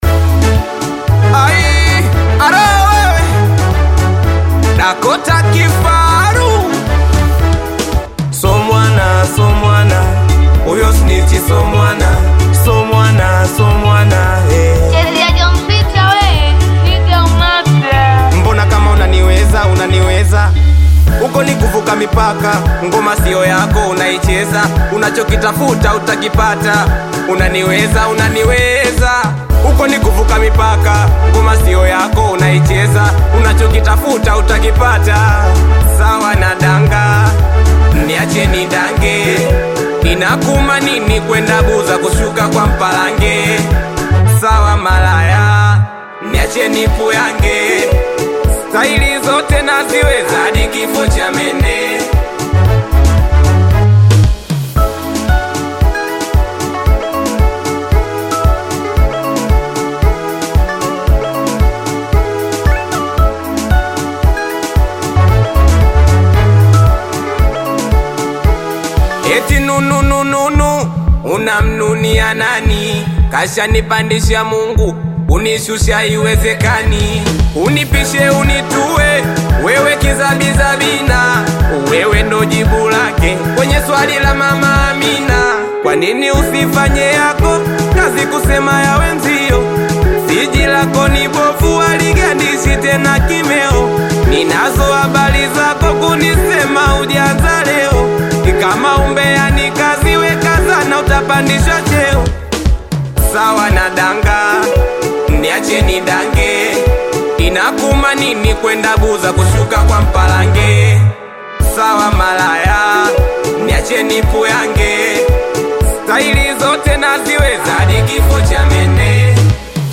Singeli music track
Bongo Flava
Singeli song